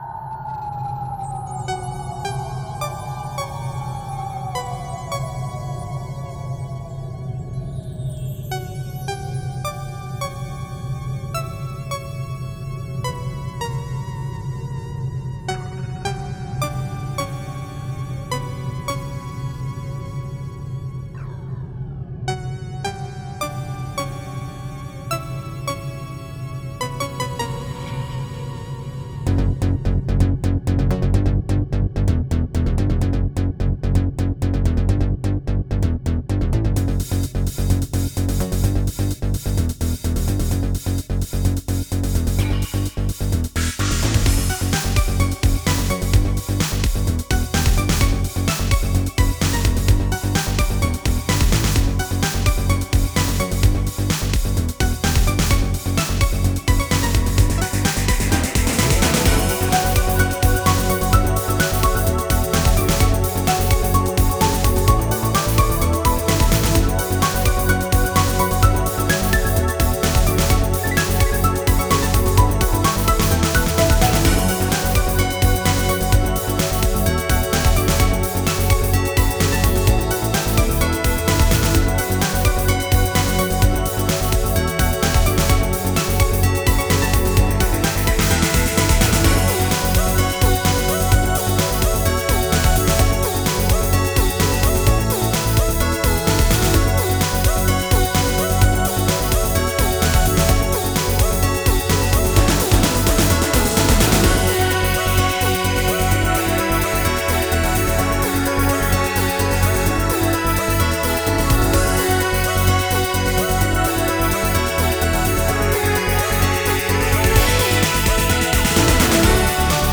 Style: Synthpop